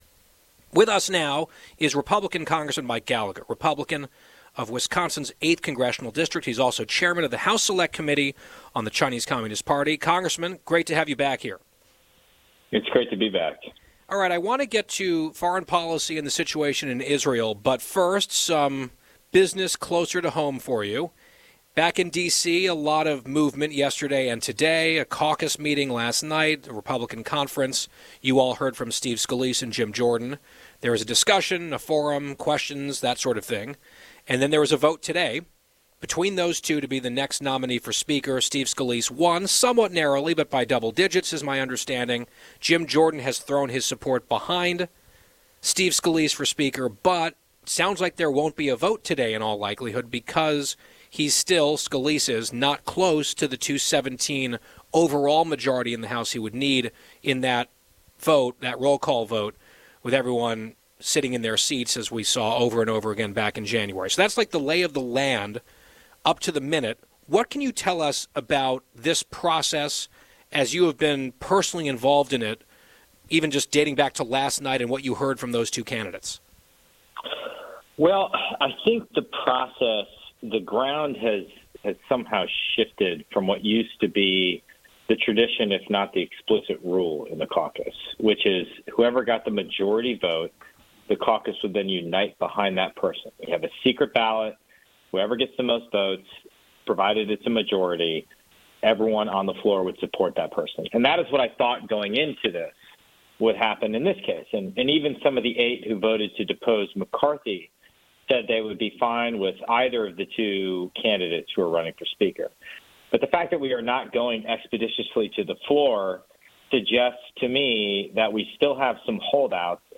Congressman Mike Gallagher (R-WI/8), Chairman of the House Select Committee on the Chinese Communist Party, a member of the House Armed Services and Intelligence Committees, and veteran joined the Guy Benson Show to discuss the current fight amongst Republicans in the House of Representatives to elect a new Speaker of the House as the debate between electing Scalise or Jordan rages on. The two also discuss the attacks on Israel via Hamas and Hezbollah, and whether or not Iran is directly involved in the conflict. Listen to the full interview below.